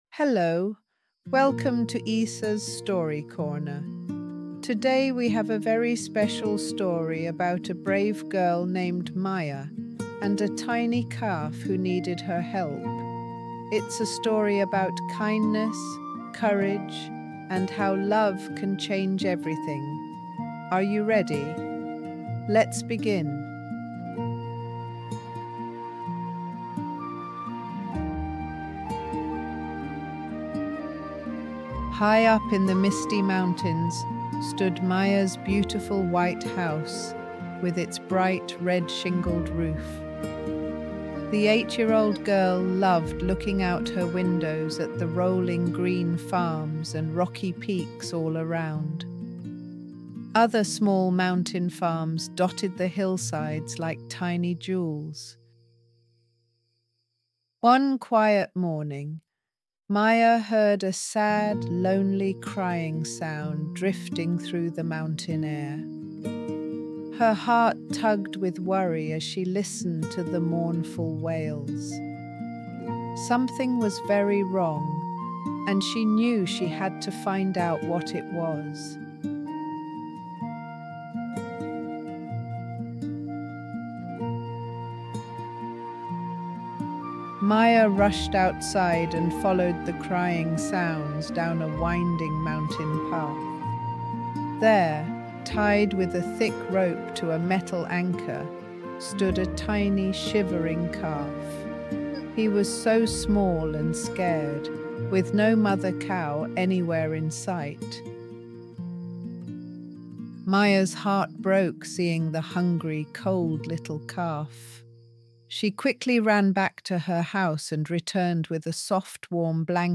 Cuento en Audio